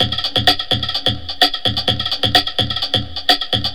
VEH1 Fx Loops 128 BPM
VEH1 FX Loop - 32.wav